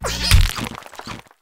tinkatink_ambient.ogg